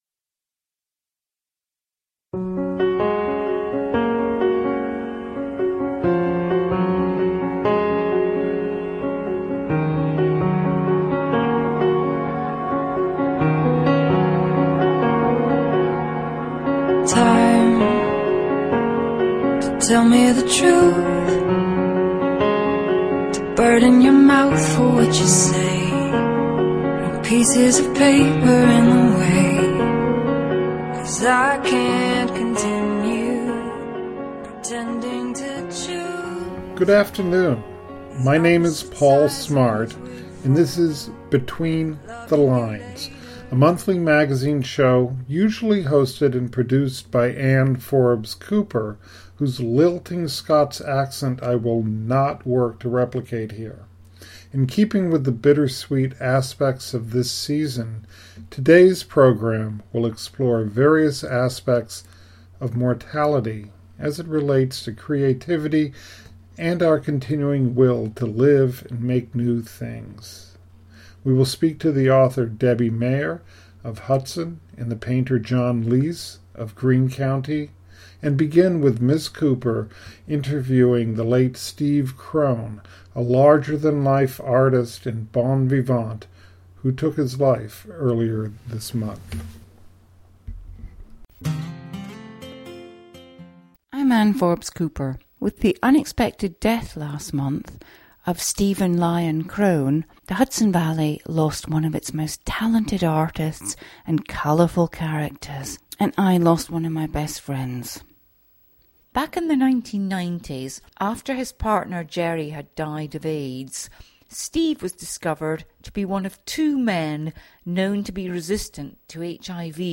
The September episode features three interviews investigating the relationship between mortality and creativity.